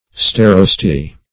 Search Result for " starosty" : The Collaborative International Dictionary of English v.0.48: Starosty \Star"os*ty\ (-[o^]s*t[y^]), n. A castle and domain conferred on a nobleman for life.